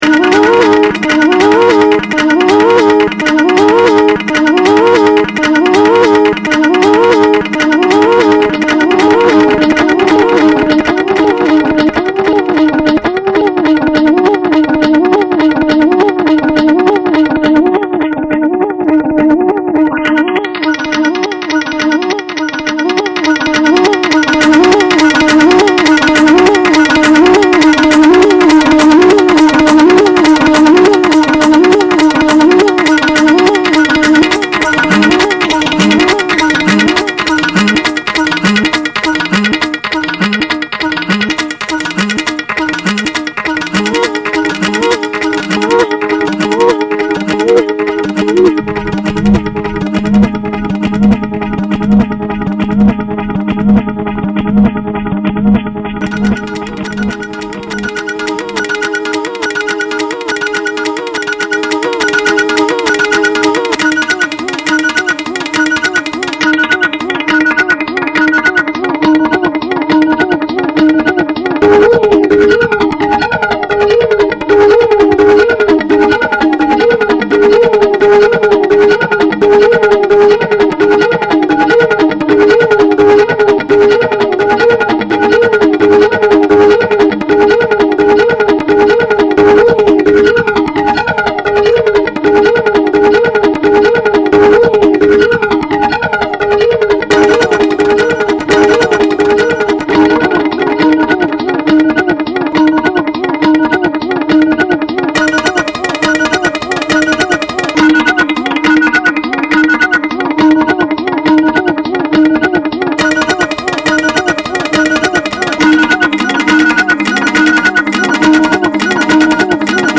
German space rock legends
Ambient Avant-Garde Electronic